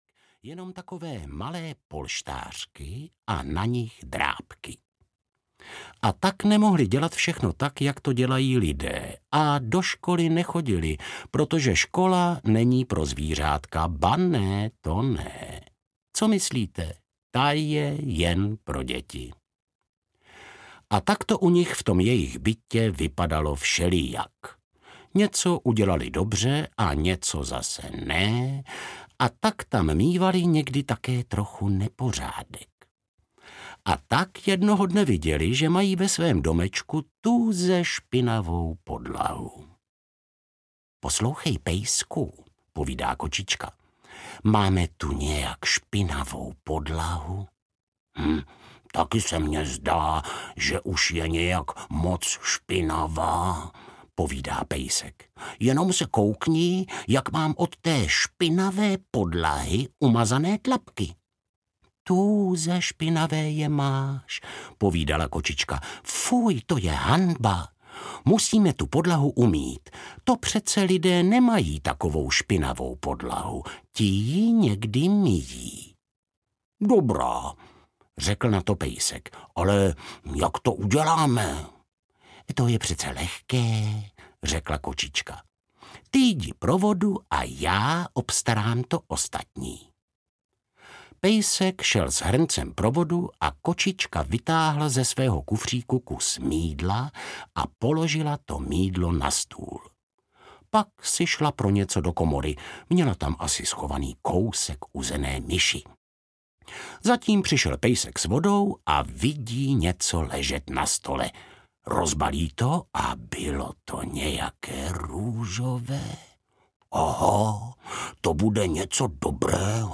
Povídání o pejskovi a kočičce audiokniha
Ukázka z knihy